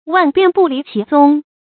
注音：ㄨㄢˋ ㄅㄧㄢˋ ㄅㄨˋ ㄌㄧˊ ㄑㄧˊ ㄗㄨㄙ